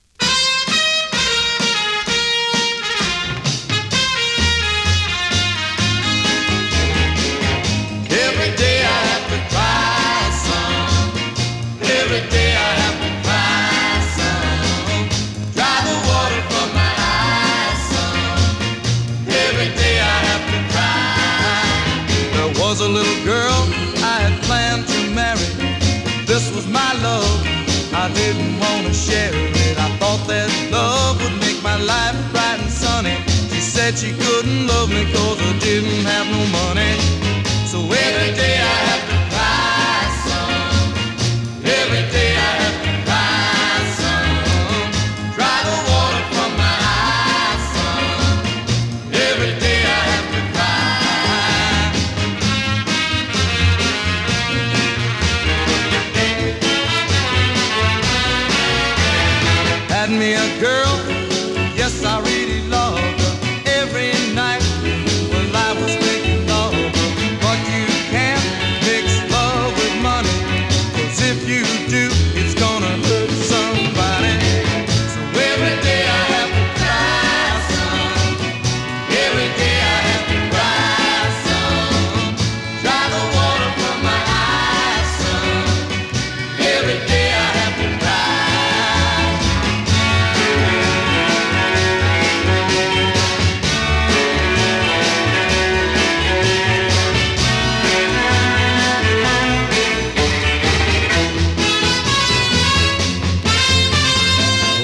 類別 R&B、靈魂樂
Great mod R&B double sider !!
試聴 (実際の出品物からの録音です)